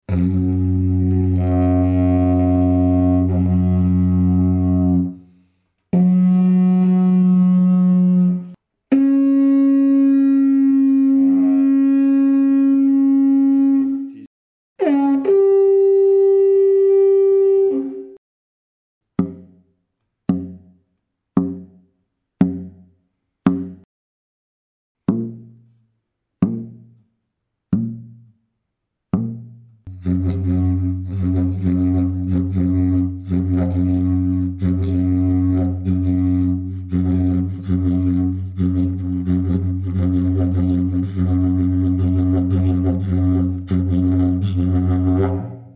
Dg520 ist ein Didgeridoo meines Modells 004 gestimmt in F#2, mit dem Overblow auf der Oktave F#3.
Der offene Konus erlaubt einen bassigen sehr lauten Grundton.
Grundton, Ziehbereiche und Overblows bei 24grd C, Kammerton A=440Hz: F#2-30 (-50, +10) // F#3-10 (-30, +0) / C#4-30 / G4-10 The Dg520 is a didgeridoo of my model 004, tuned to F#2, with the overblow on the octave F#3. The fundamental tone is 30 cents lower than F# at concert pitch 440 Hz, so the didgeridoo would be well-suited for a tuning of concert pitch A=432 Hz.
The open cone allows for a bassy, very loud fundamental tone.
Fundamental note, draw ranges and overblows at 24� C, concert pitch A=440Hz: F#2-30 (-50, +10) // F#3-10 (-30, +0) / C#4-30 / G4-10 Dg520 Technical sound sample 01